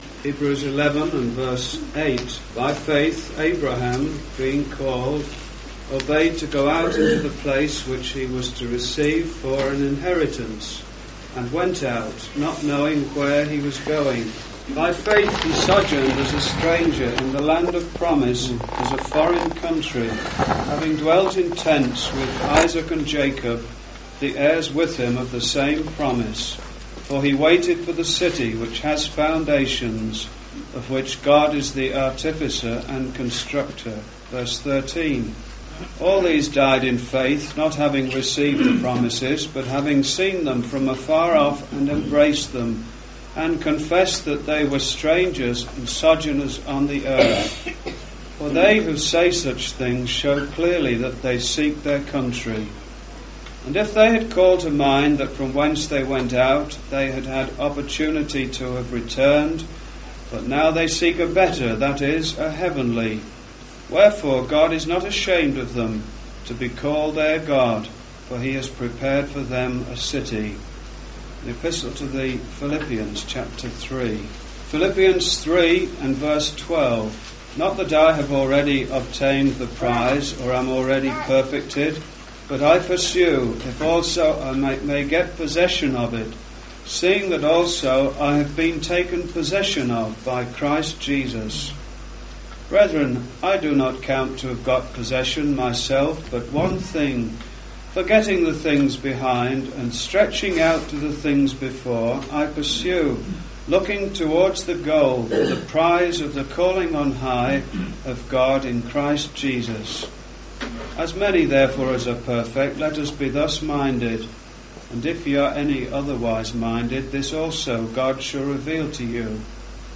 In this address, you will hear of the importance for the believer to seek Christ as we are travelling through this world.